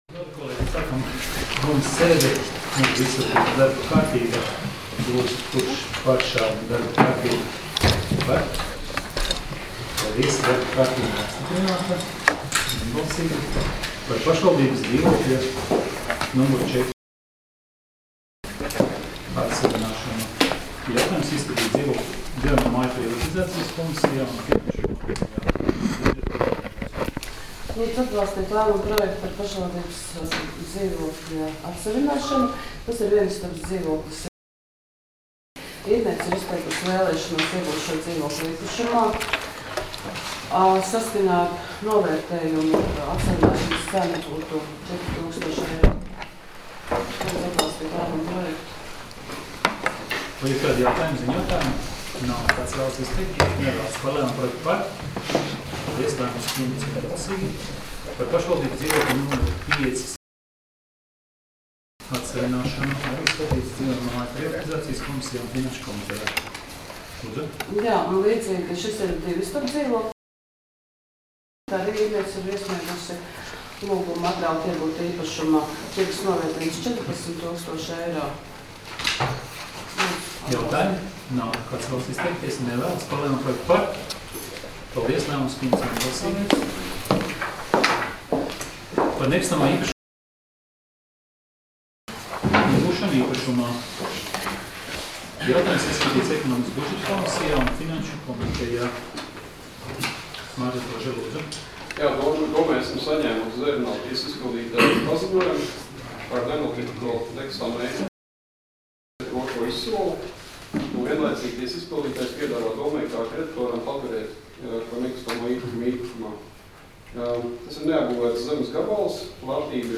Domes sēdes 04.01.2019. audioieraksts